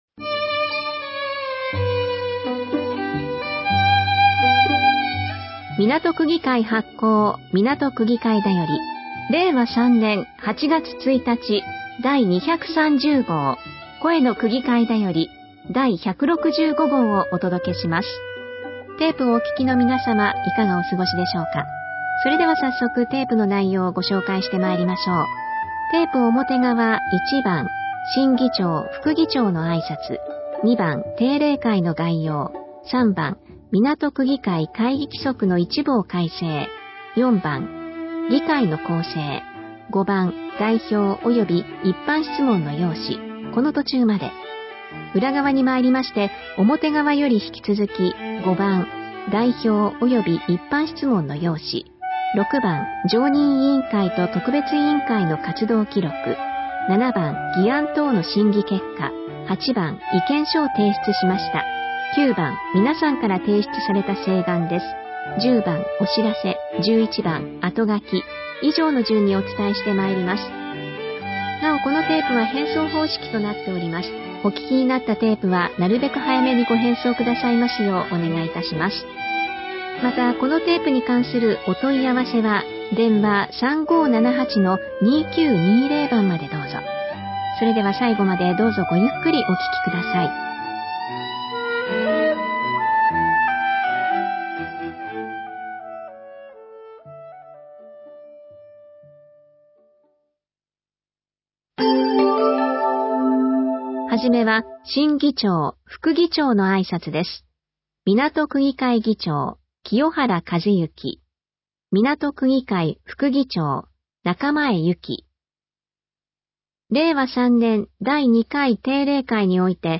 掲載している音声ファイルは、カセットテープで提供している音声ファイルをそのまま掲載しています。そのため、音声の冒頭で「テープの裏側にまいりました」のような説明が入っています。